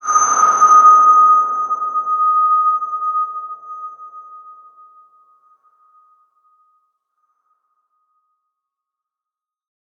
X_BasicBells-D#4-mf.wav